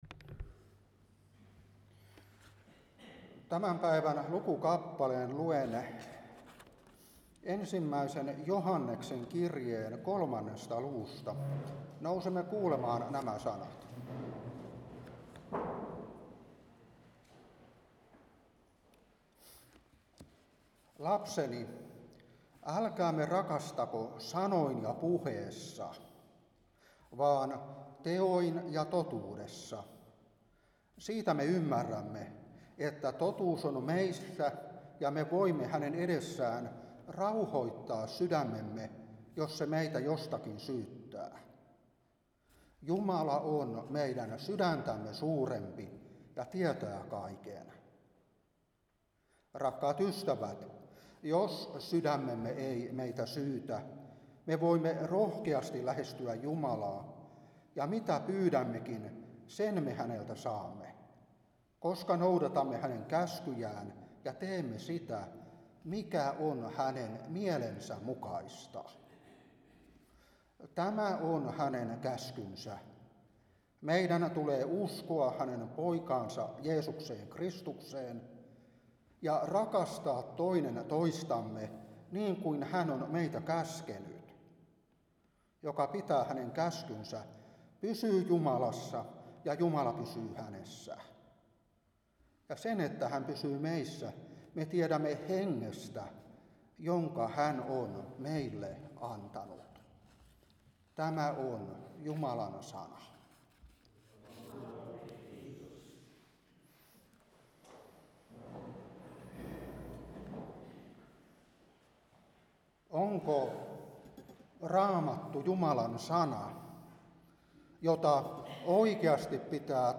Saarna 2024-4. 1,Joh.3:18-24. 1.Joh.4:10.